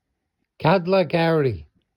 6. кадлакэури